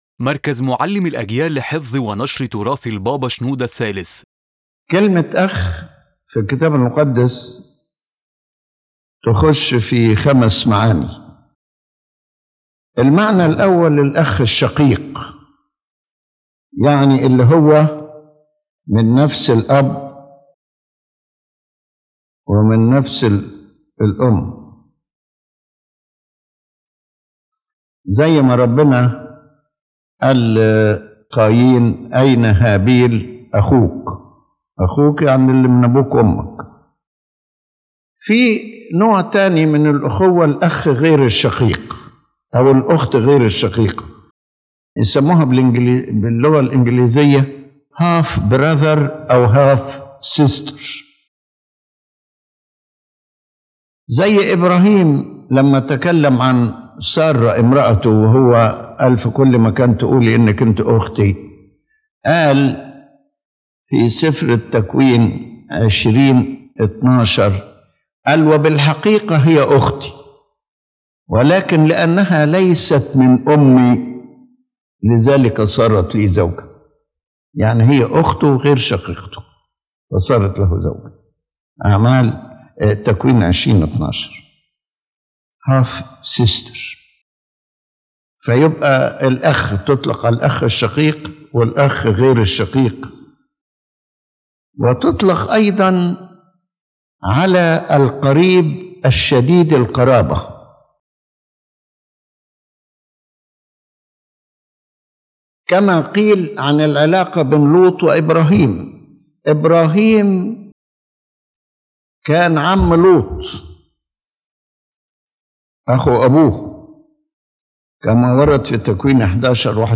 His Holiness Pope Shenouda III explains in this lecture the multiple meanings of the word “brother” in the Holy Bible, showing that the term is not limited to the biological brother but covers various levels of human and spiritual kinship.